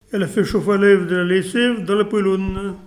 Localisation Nieul-sur-l'Autise
Catégorie Locution